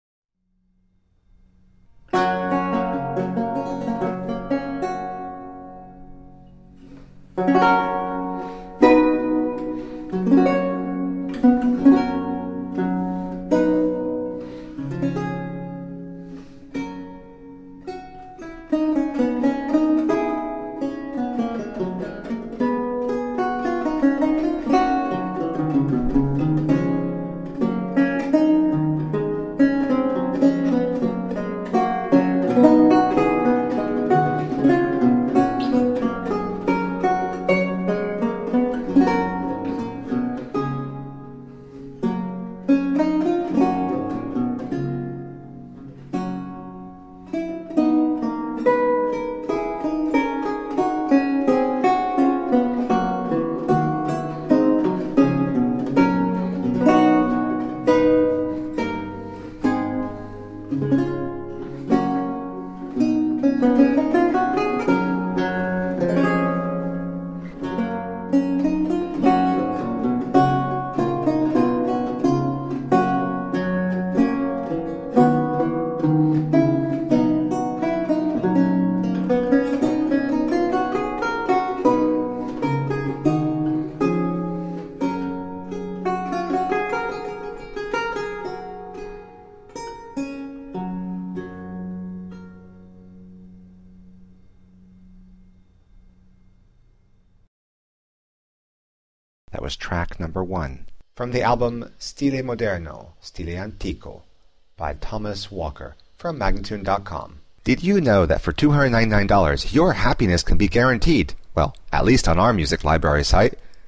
Lute music of 17th century france and italy.